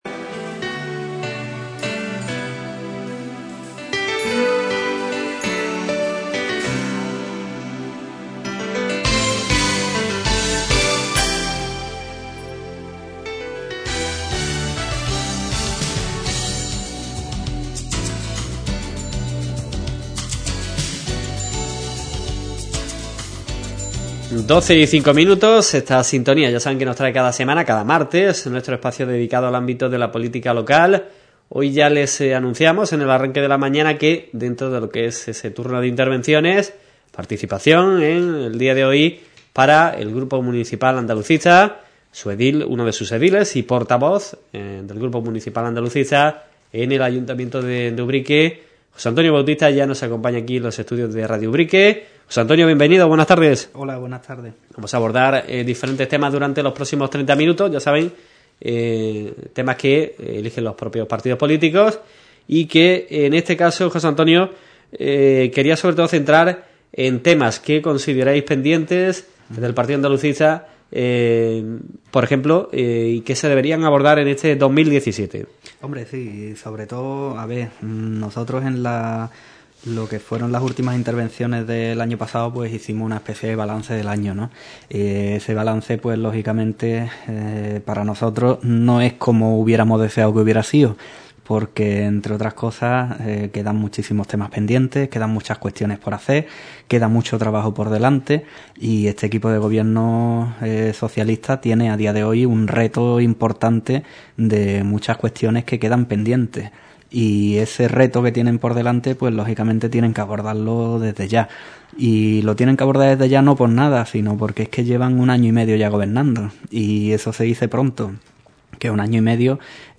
José Antonio Bautista, edil y portavoz del Grupo Municipal Andalucista en el espacio de política ‘La Mañana’ de Radio Ubrique